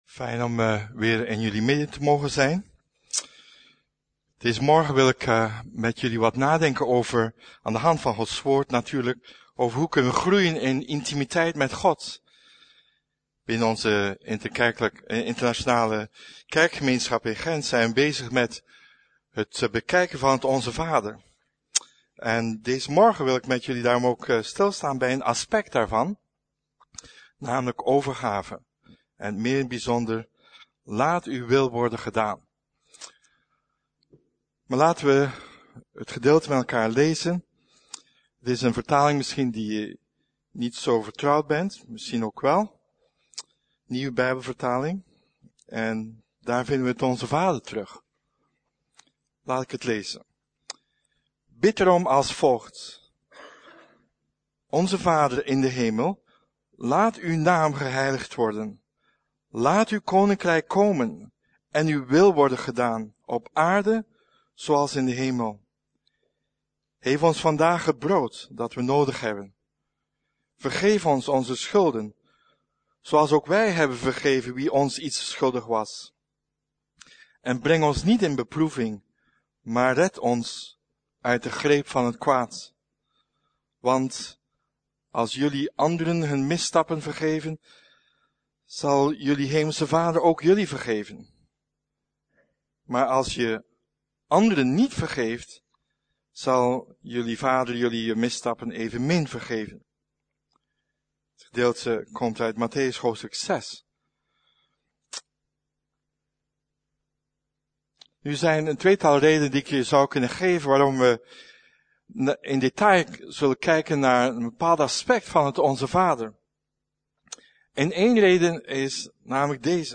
Preek: Groeien in intimiteit met God - Levende Hoop